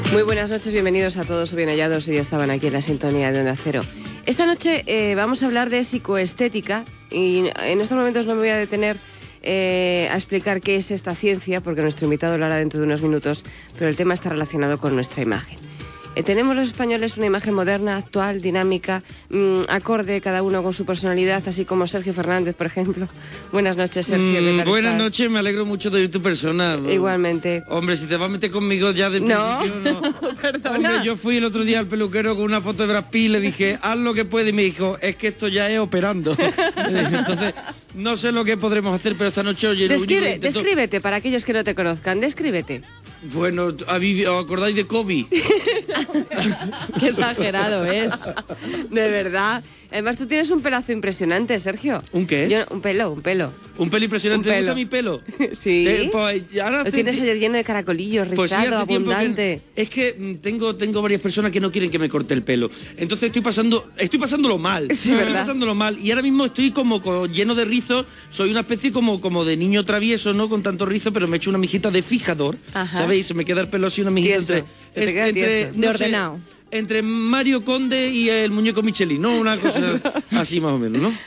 Conversa sobre psicoestètica amb l'humorista Sergio Fernández "El monaguillo".
Entreteniment